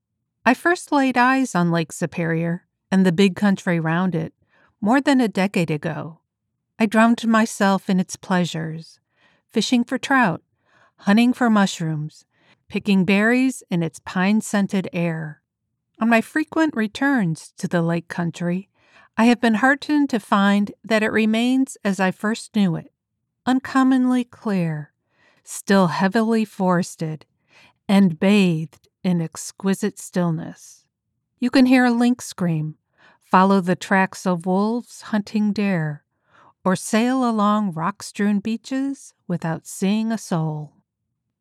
Professional Female Voice Over Talent
Memoir Sample
Let’s work together to bring your words to life with my conversational, authoritative and articulate voice.